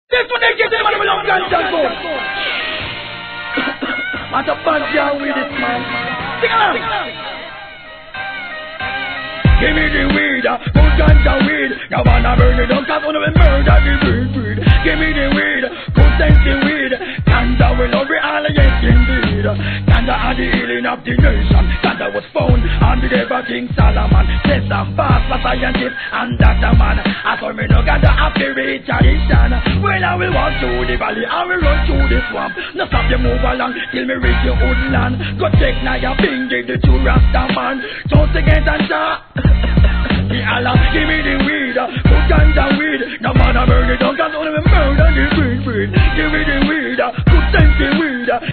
REGGAE
REGGAE大ヒット作のHIP HOP REMIX!!